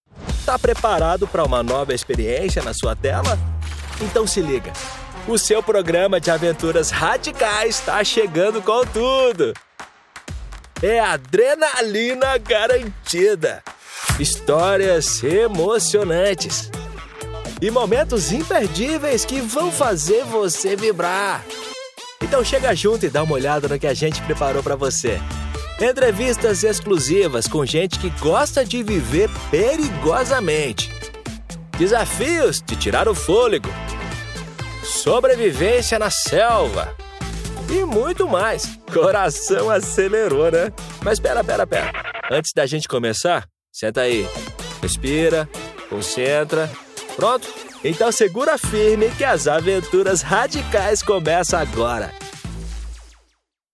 Com uma gama de graves e contralto e configuração de estúdio profissional, ele oferece serviços de locução amigáveis e especializados para marcas que buscam clareza e autenticidade.
Programas de televisão
Focusrite Scarlet Solo + microfone Akg c3000
GravesContralto
DinâmicoNeutroAmigáveisConversacionalCorporativoVersátil